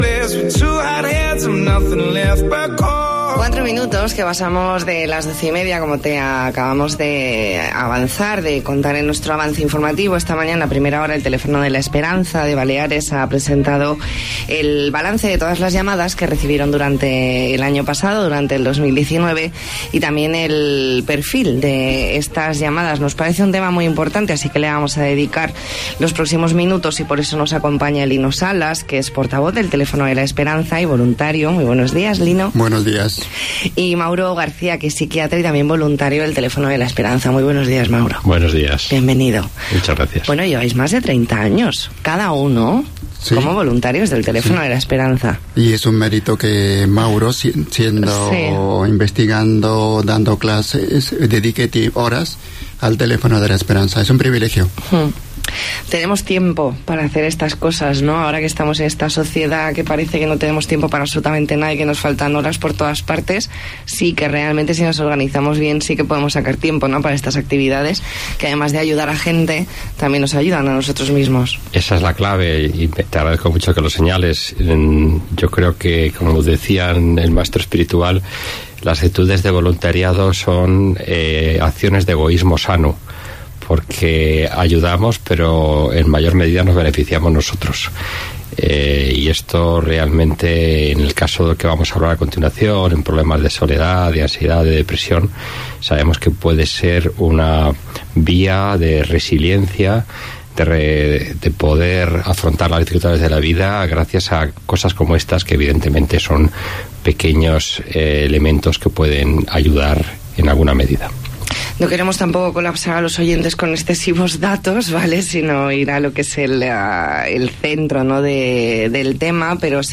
Entrevista en La Mañana en COPE Más Mallorca, martes 3 de marzo de 2020.